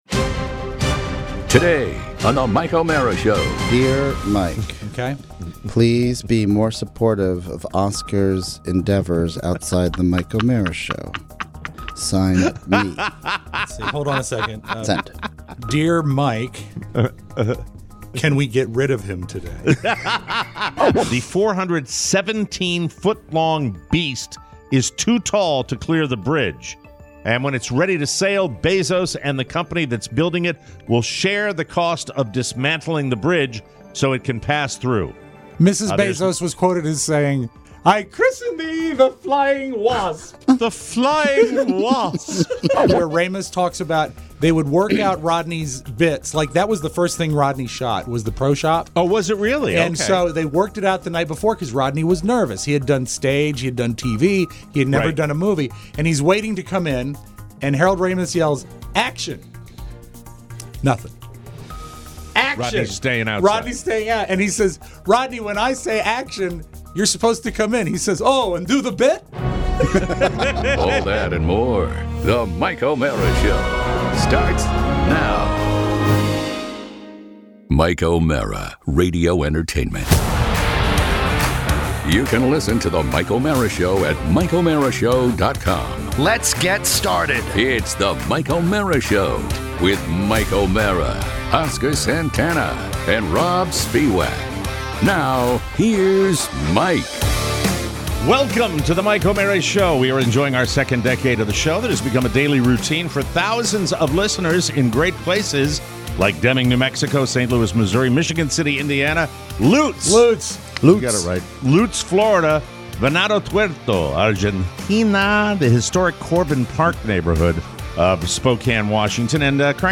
Today, we debut new music and a few sounds that will tickle your ears (and your fancy!).